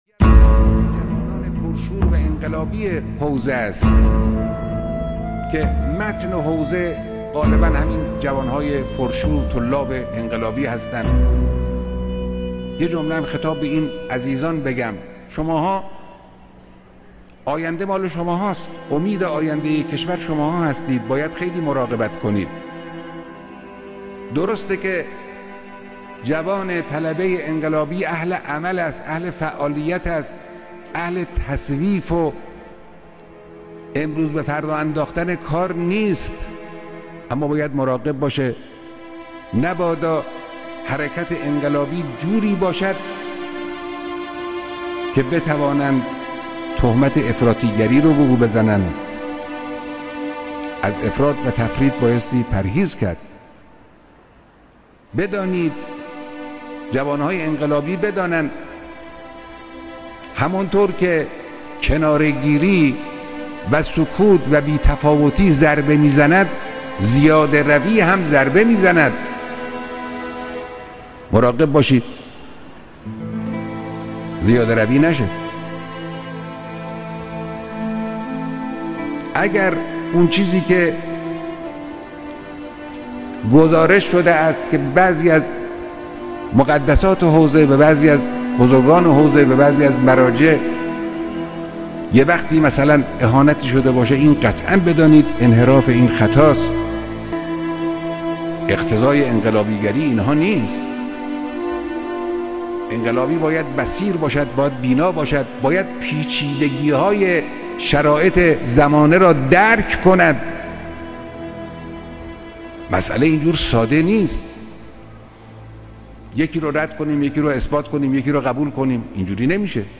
کلیپ صوتی بسیار زیبا از توصیه های مهم حضرت امام خامنه ای(مدظله العالی)